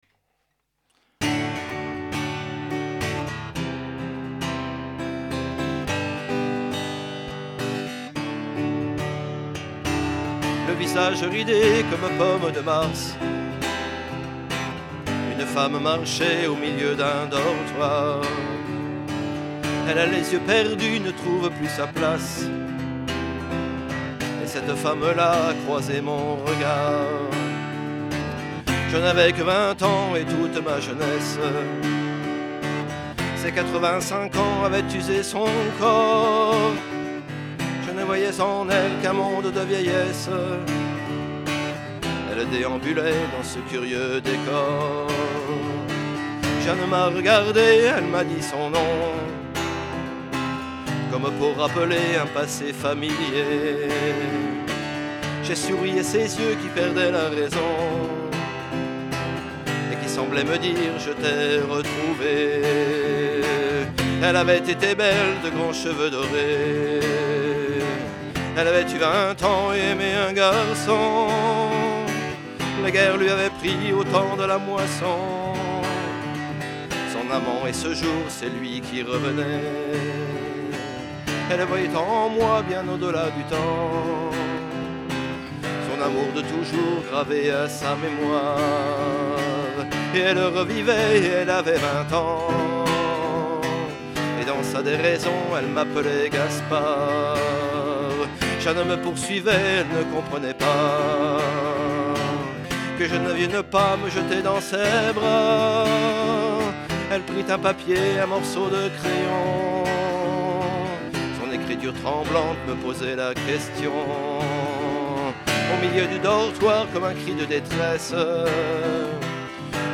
guitare